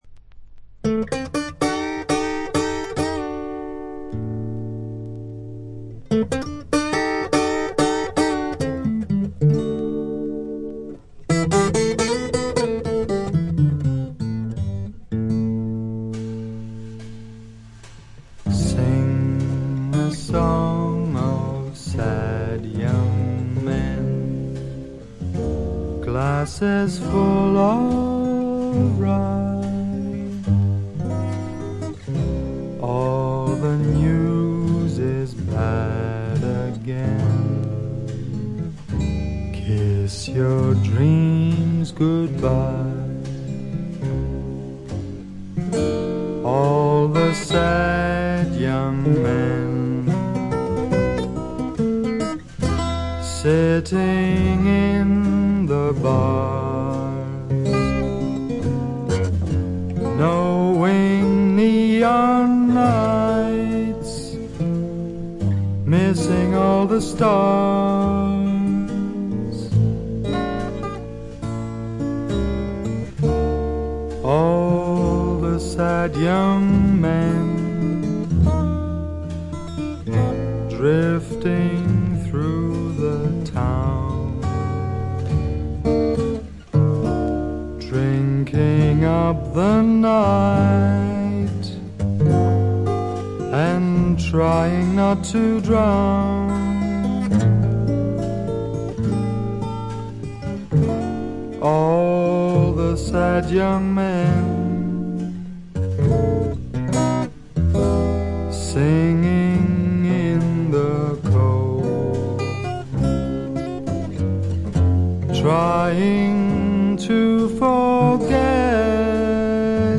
ごく軽微なチリプチやバックグラウンドノイズがところどころで出る程度、良好に鑑賞できます。
フォーク、ブルース、ラグ、ジャズ等の要素を混ぜ合わせたスタイルは、独特の無国籍感とアシッド感覚を醸し出しています。
試聴曲は現品からの取り込み音源です。